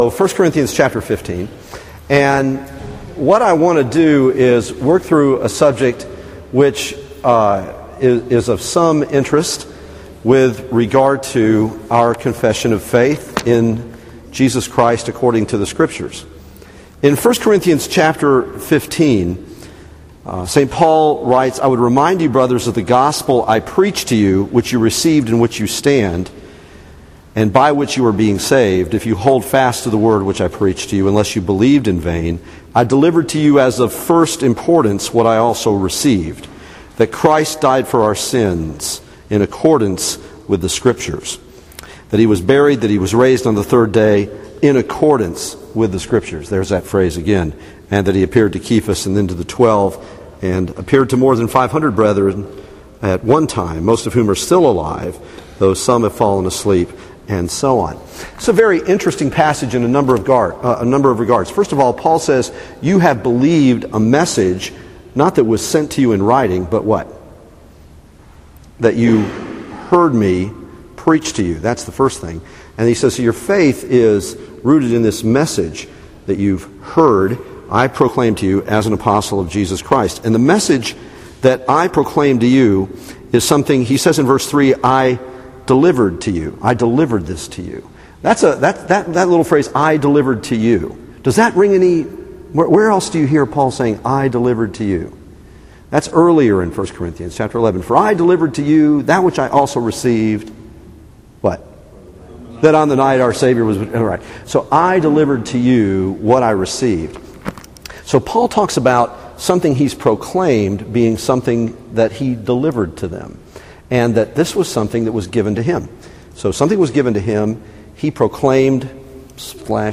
Service Type: Sunday Evening Topics: The Apostles Creed